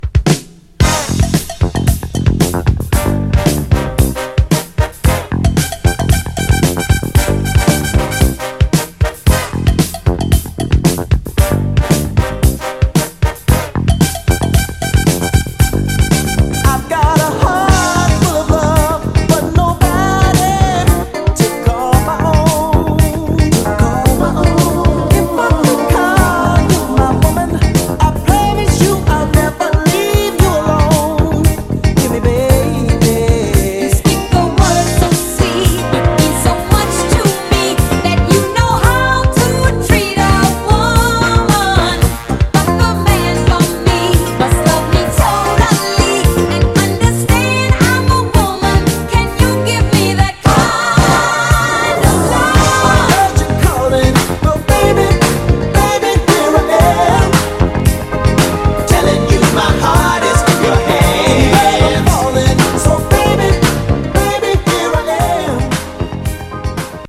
> DISCO 45's
弾ける爽快感が爆発する大人気ダンクラ/ブギー定番曲です！
(Stereo)